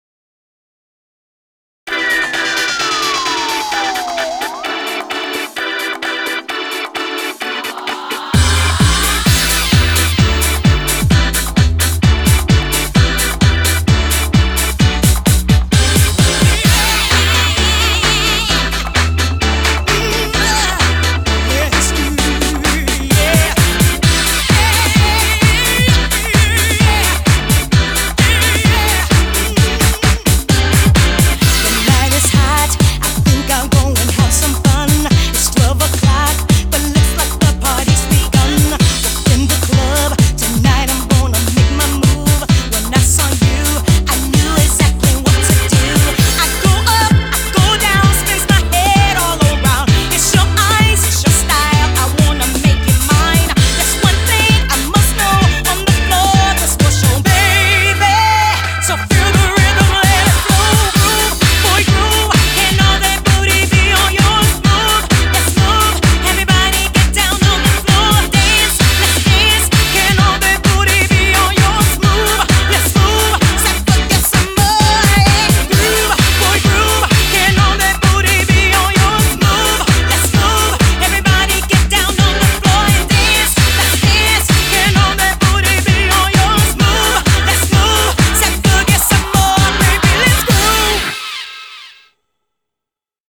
BPM130
Audio QualityLine Out